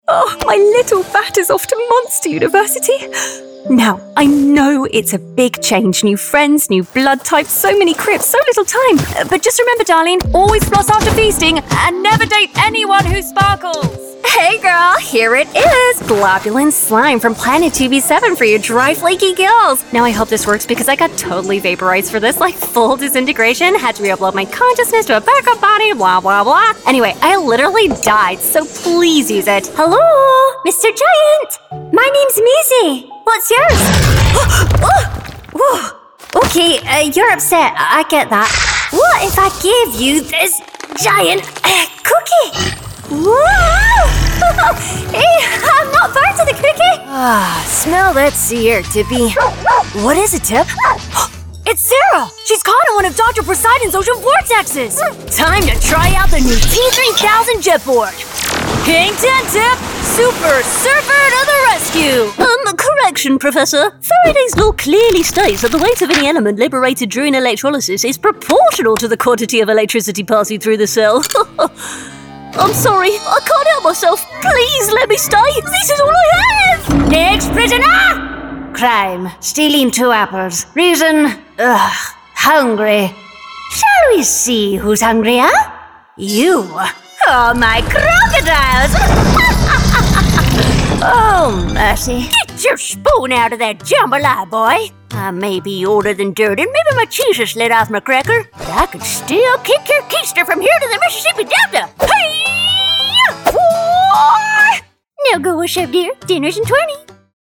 Female
Bright, Character, Cool, Engaging, Friendly, Natural, Soft, Warm, Witty, Versatile, Approachable, Conversational, Funny, Upbeat, Young
Microphone: Rode NT1-A
Audio equipment: Focusrite Scarlett 2i2, bespoke built vocal isolation booth